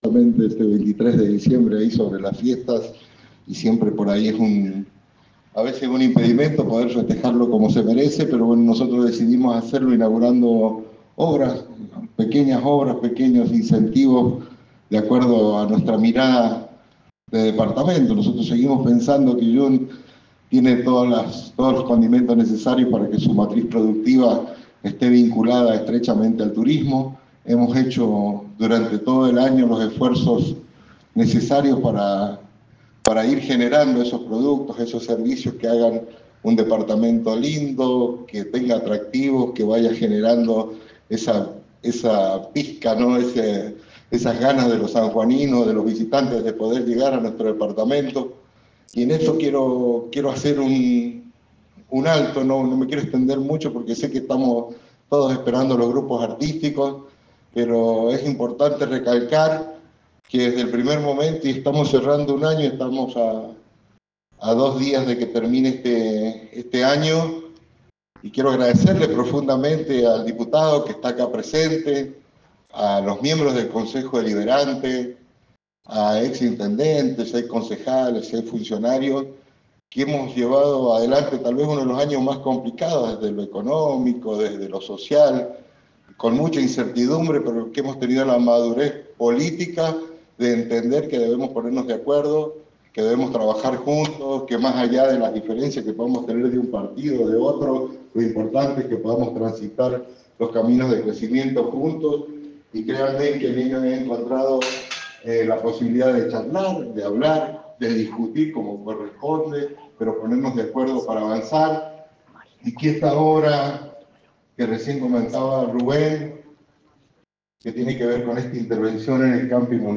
Palabras del Intendente David Domínguez